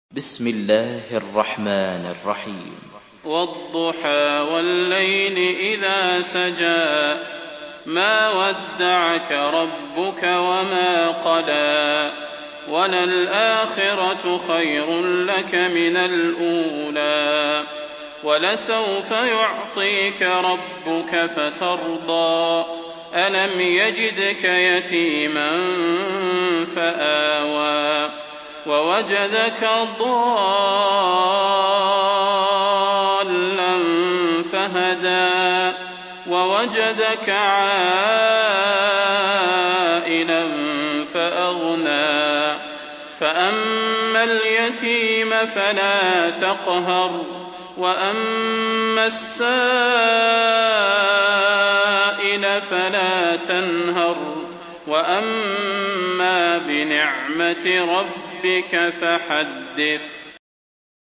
Surah Duha MP3 in the Voice of Salah Al Budair in Hafs Narration
Murattal